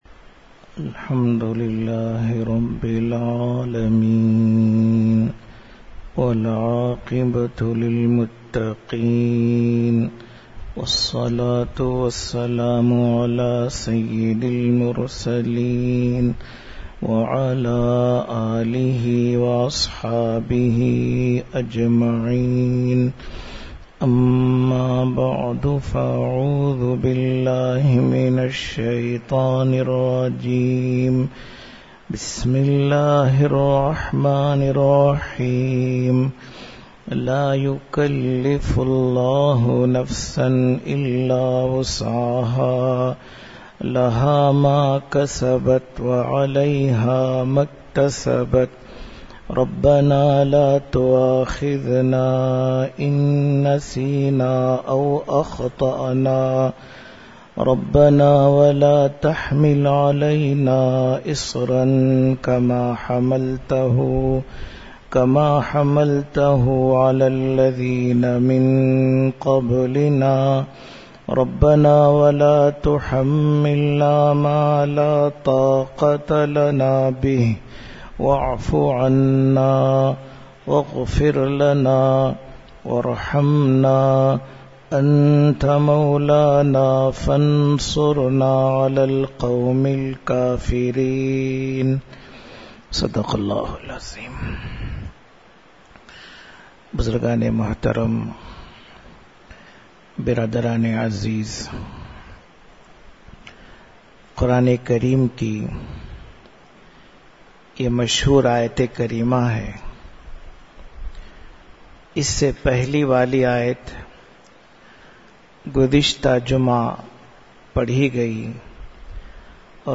Bayaan